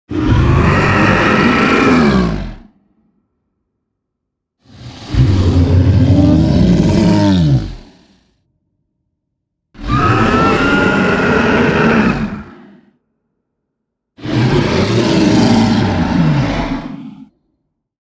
Dragon Die Sound
horror